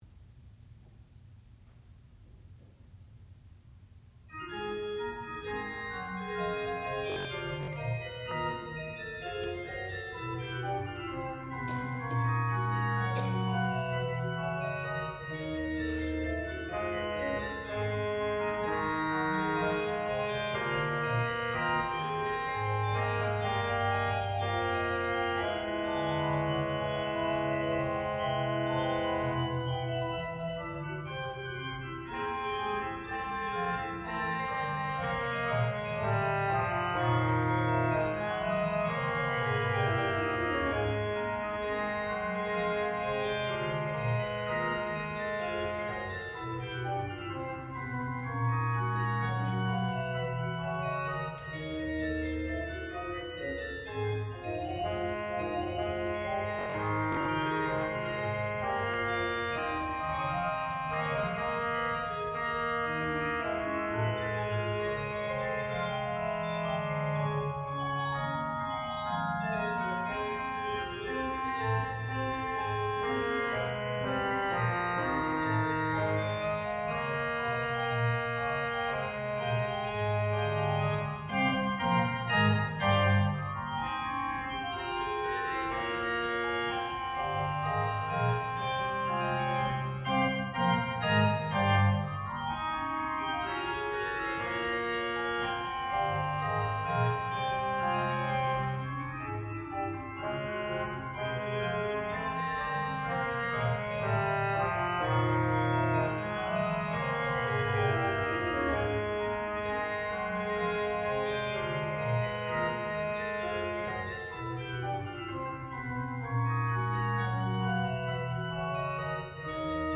classical music
organ
Master's Recital